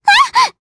Pansirone-Vox_Damage_jp_02.wav